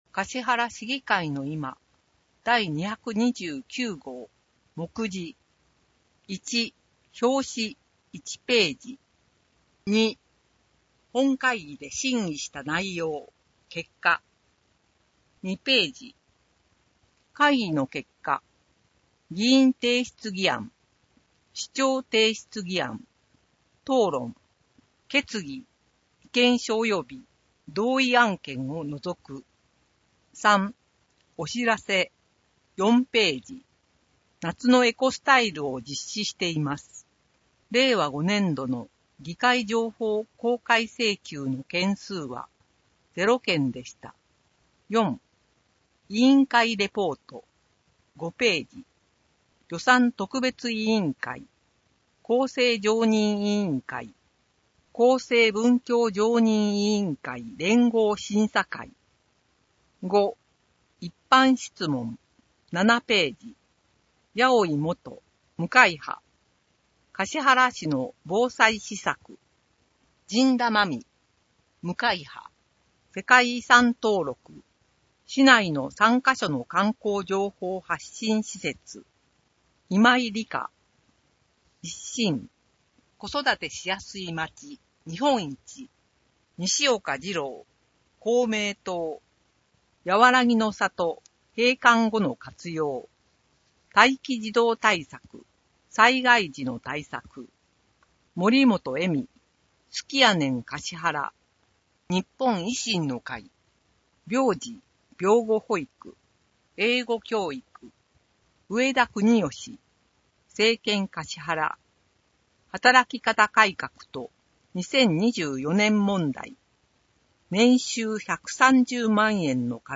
音訳データ
音訳データは、音訳グループ「声のしおり」の皆さんが音訳されたものを使用しています。